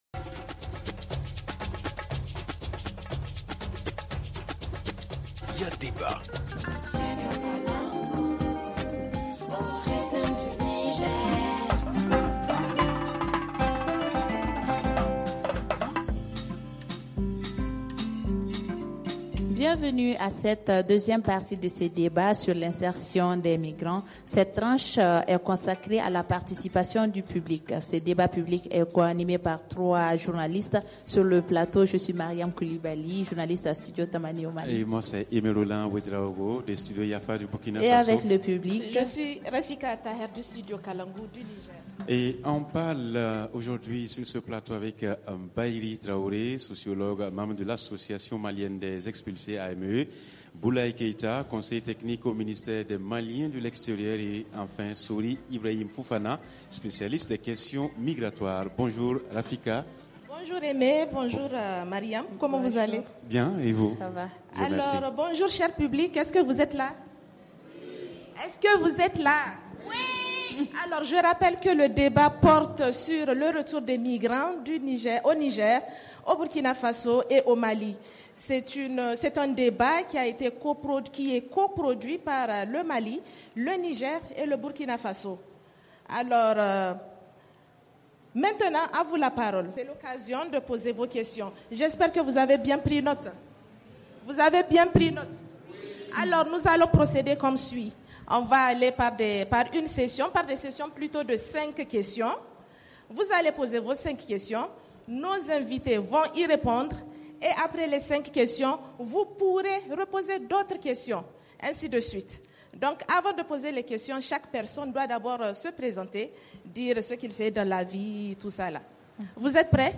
Studio Kalangou, Studio Yafa et Studio Tamani posent le débat à Bamako au Mali.